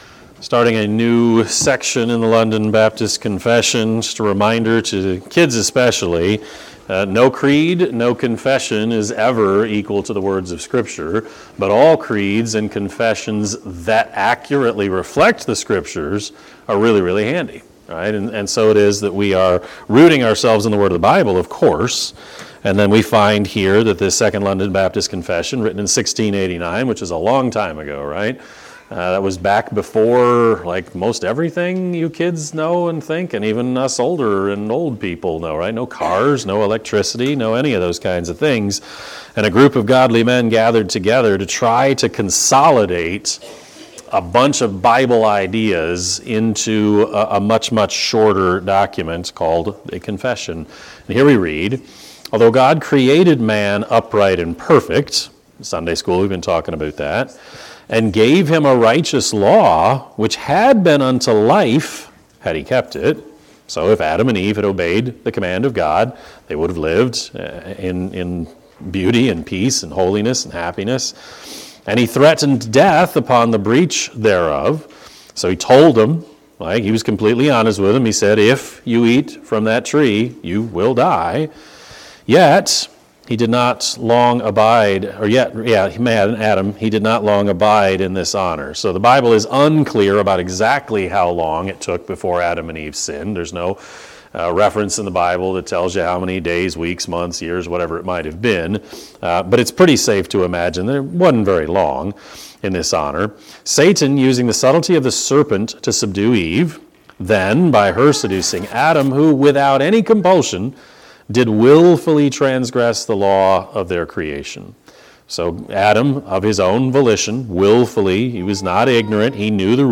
Sermon-Edit.8-3-25.mp3